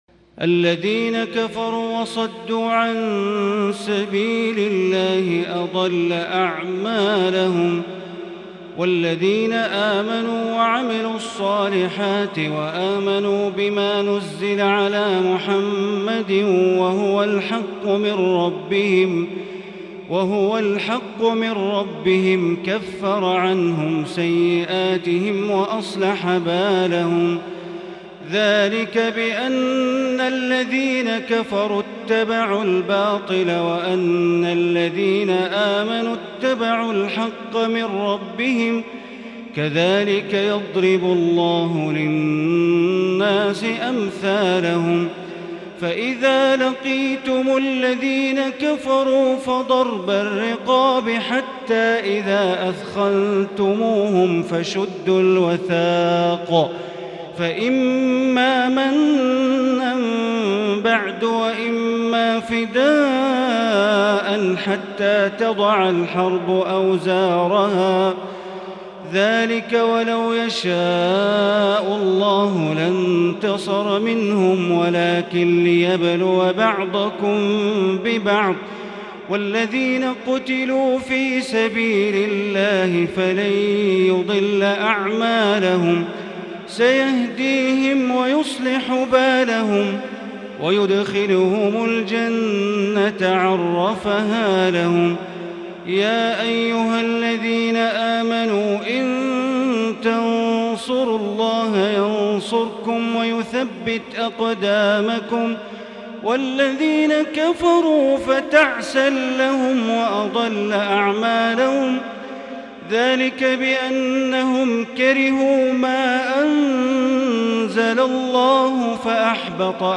Tarawih prayer on the night of the twenty-seventh of Ramadan for the year 1441 recited from Surah Muhammad and to Surah Al-Fath verse 17 > 1441 > Taraweeh - Bandar Baleela Recitations